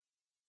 ambience_frog.ogg